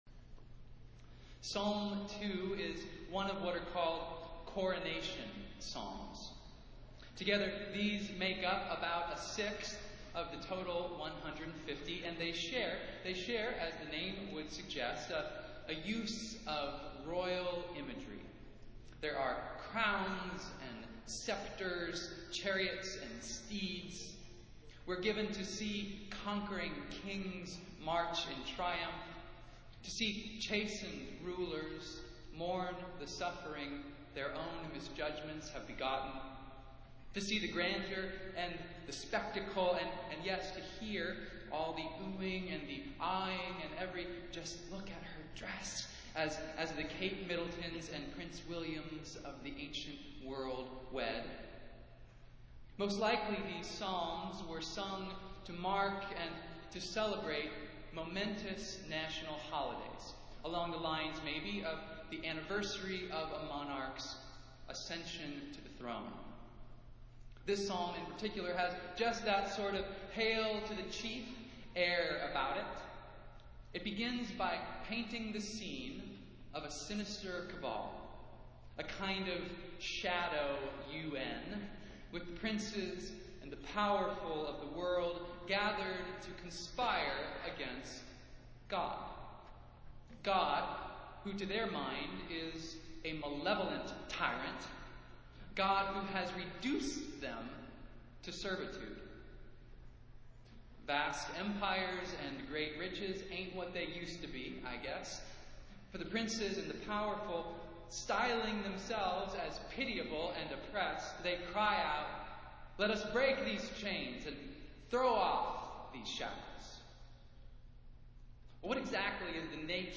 Festival Worship - Sixteenth Sunday after Pentecost